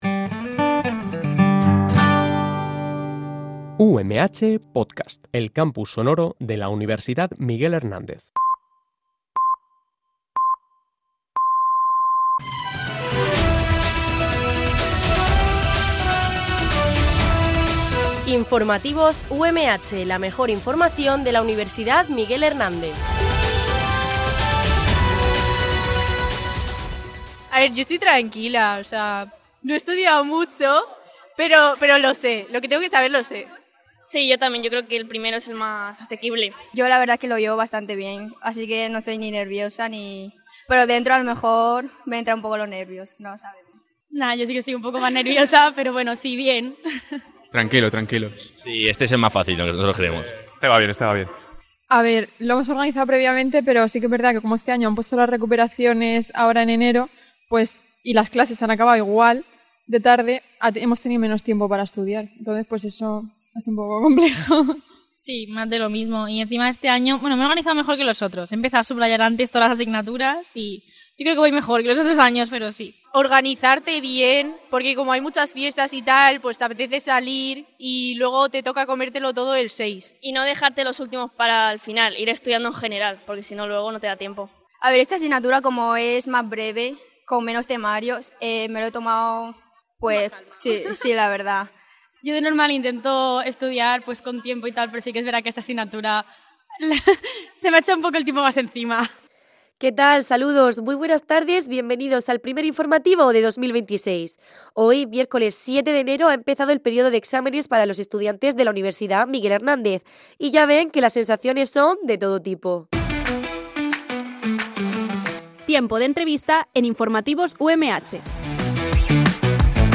Este programa de noticias se emite en directo, de lunes a viernes, en horario de 13.00 a 13.10 h.